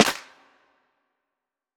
kits/Cardiak/Snares/HFMSnare6.wav at main
HFMSnare6.wav